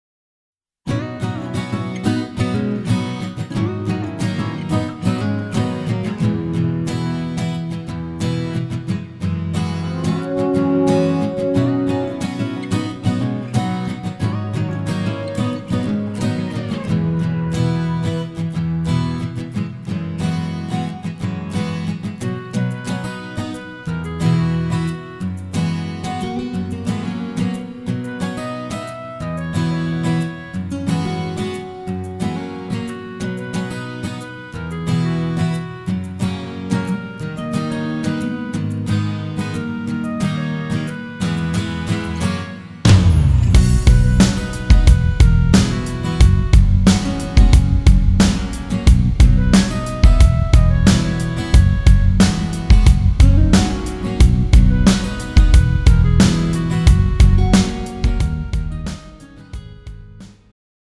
음정 -2키
장르 축가 구분 Pro MR